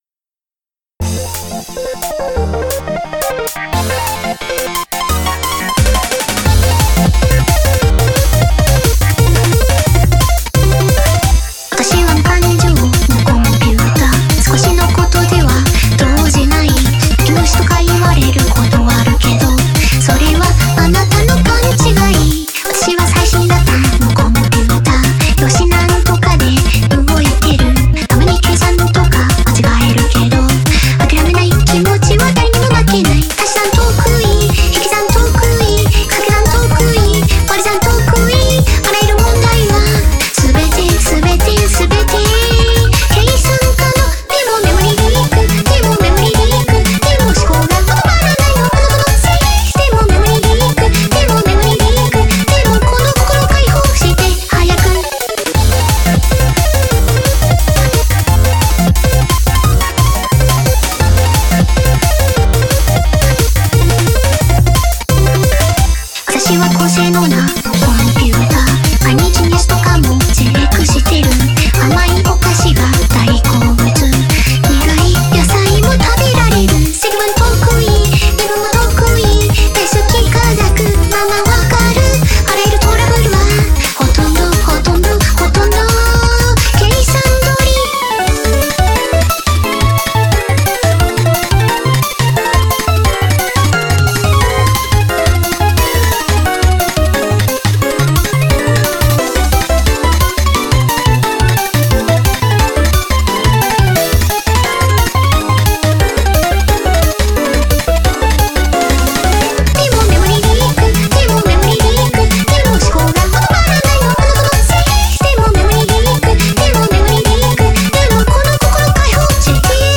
MP3 avec chant: